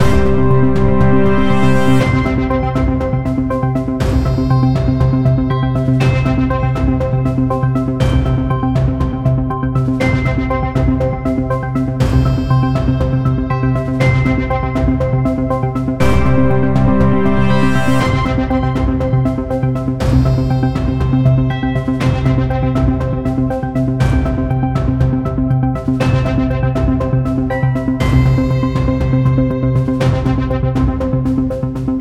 Game Music